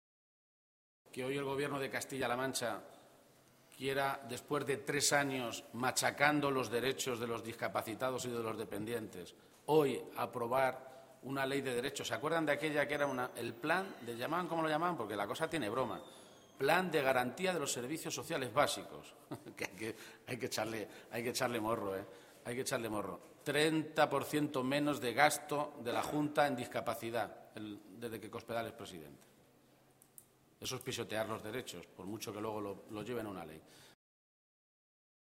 García-Page se pronunciaba de esta manera esta mañana, en Toledo, a preguntas de los medios de comunicación, y recordaba que “desde que gobierna Cospedal, el gasto público del Gobierno regional en discapacidad ha descendido un 30 por ciento”.
Cortes de audio de la rueda de prensa